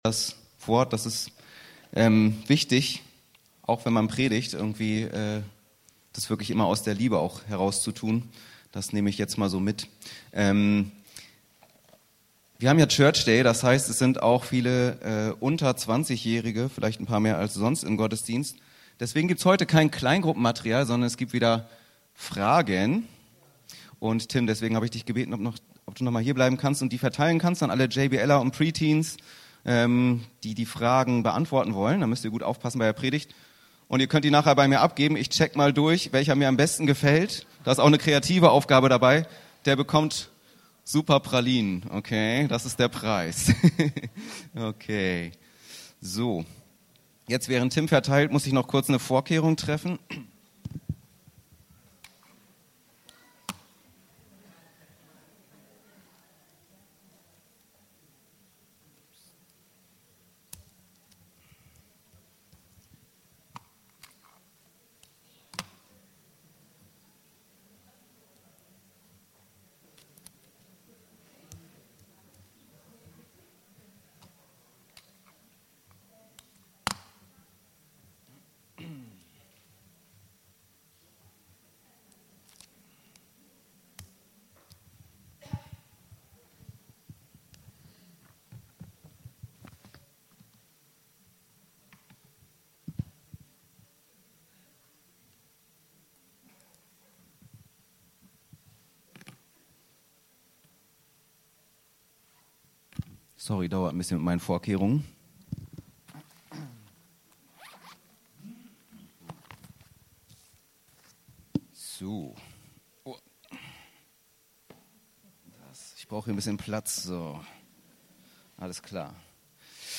Predigten Podcast